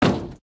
Divergent / mods / Footsies / gamedata / sounds / material / human / step / tin2.ogg